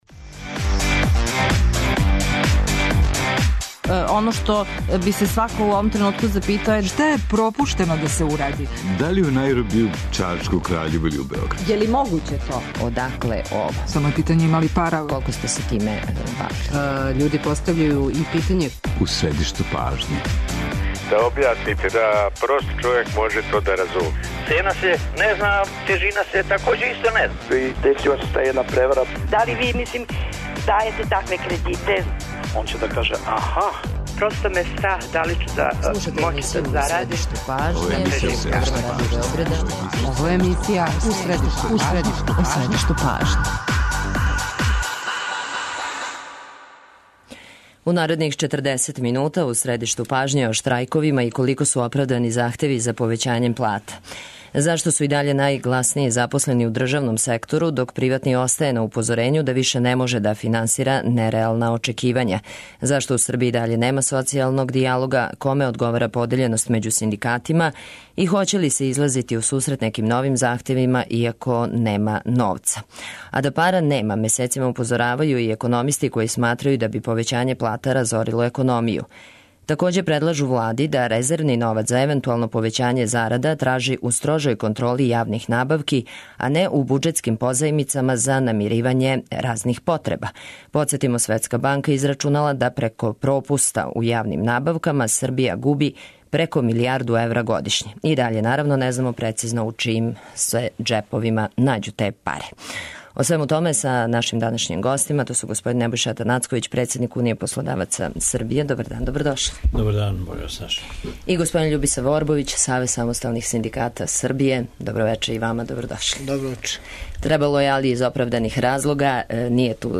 доноси интервју са нашим најбољим аналитичарима и коментаторима, политичарима и експертима